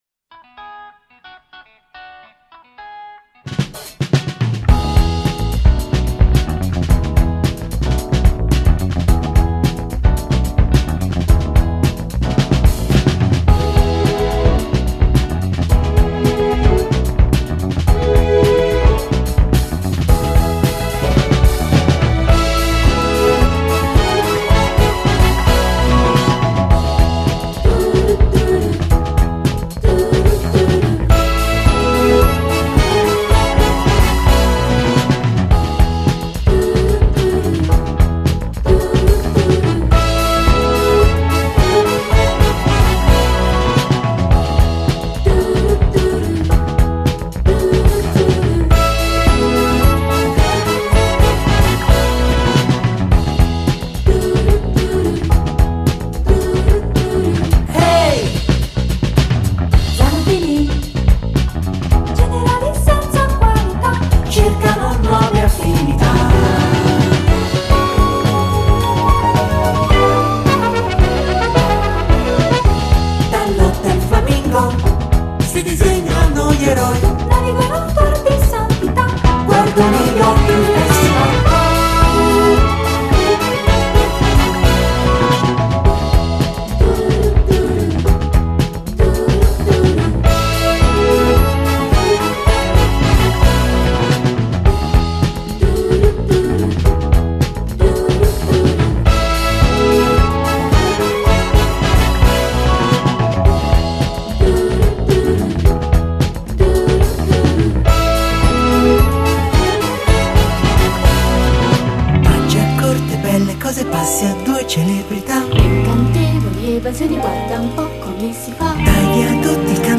a disco touch